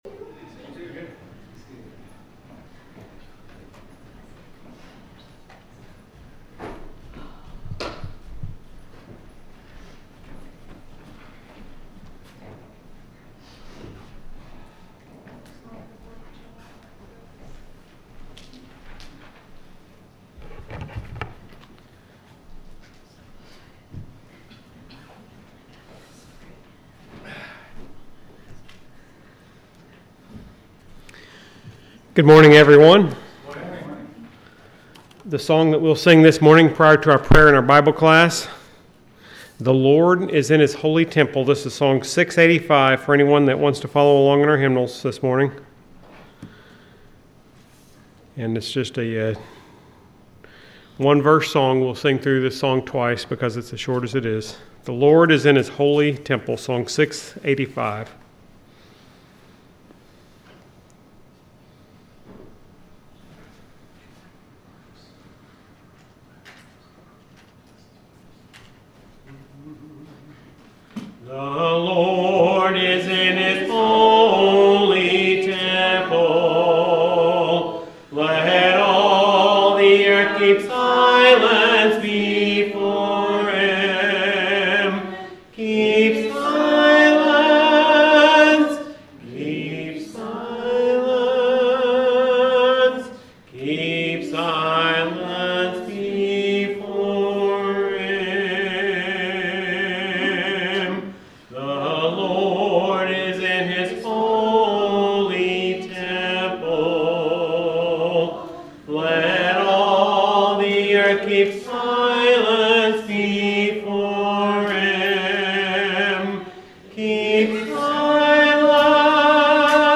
The sermon is from our live stream on 7/20/2025